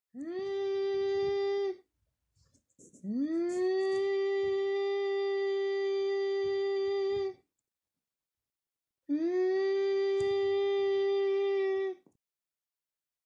描述：sounds of the entry into elevator and while it is moving
标签： inside sounds entering lift elevator into
声道立体声